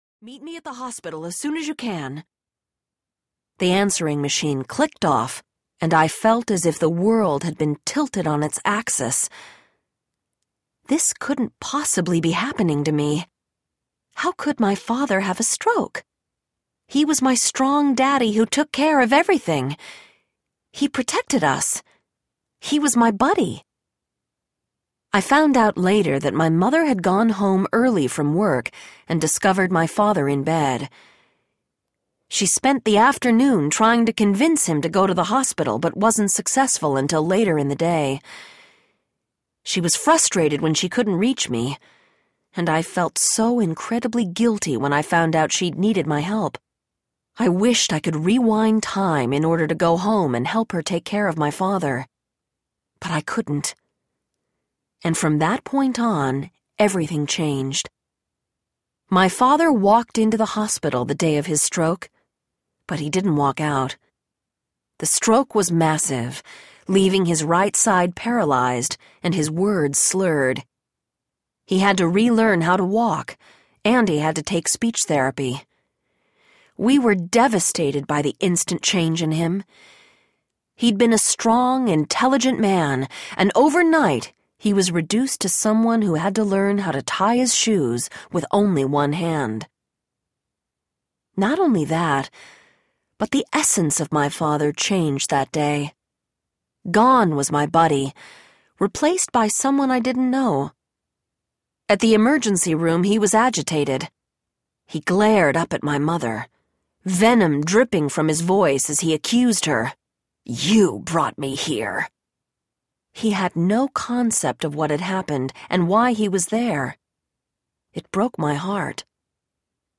The Gift of Love Audiobook
Narrator
6.06 Hrs. – Unabridged